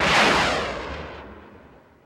rocketFlame.ogg